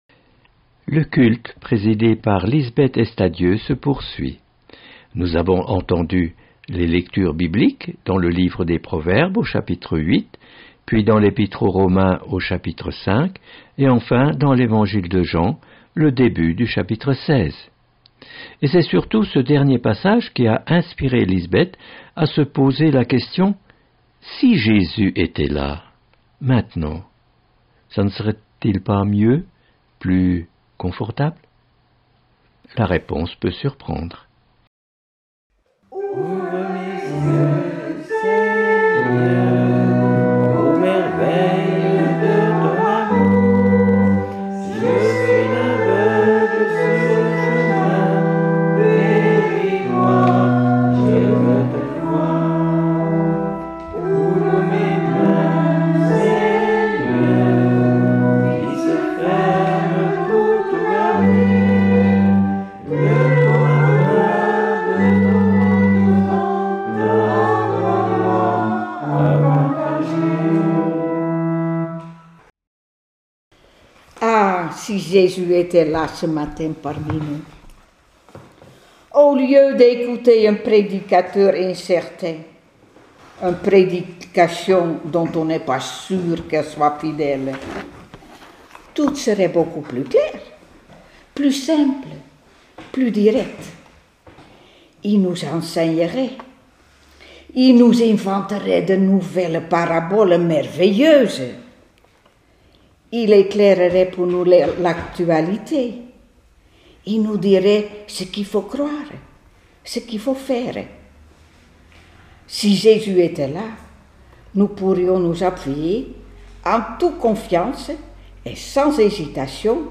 Suite du culte présidé